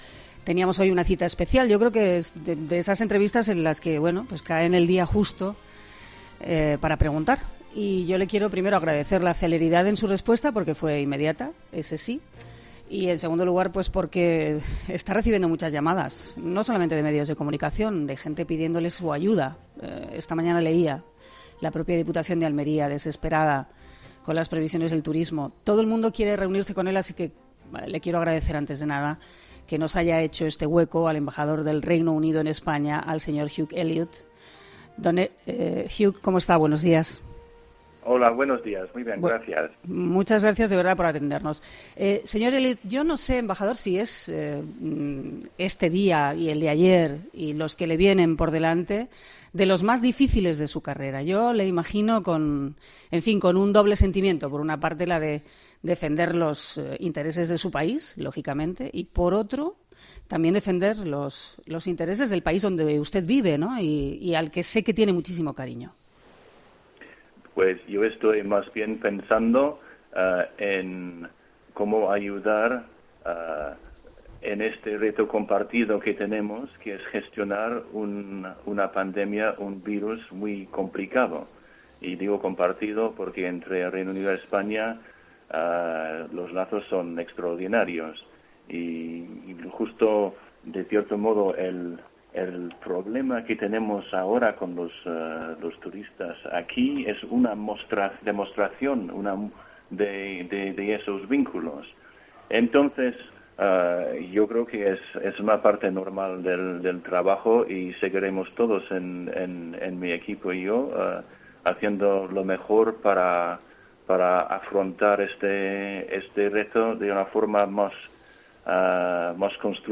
El embajador del Reino Unido, en COPE: Va a seguir habiendo conversaciones, pero es un momento difícil - Al respecto se ha pronunciado hoy en COPE el embajador del Reino Unido.